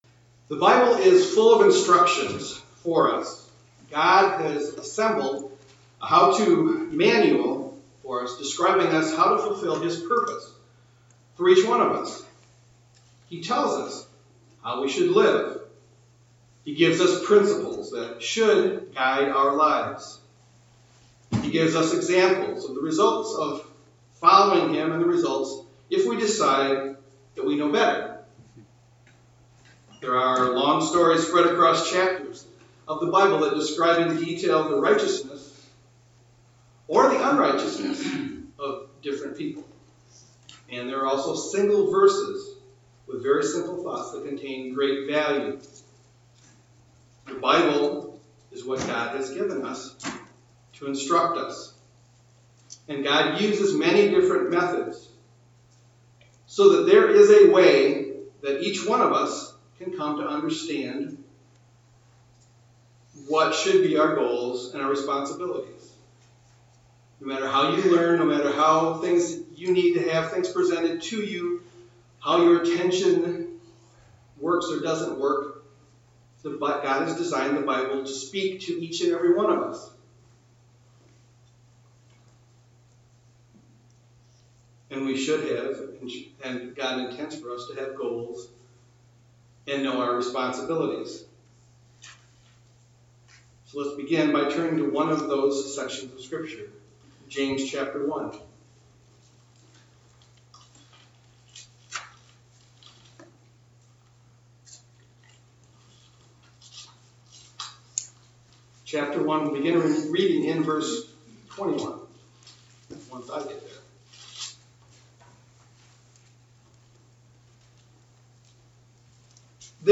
Sermons
Given in Grand Rapids, MI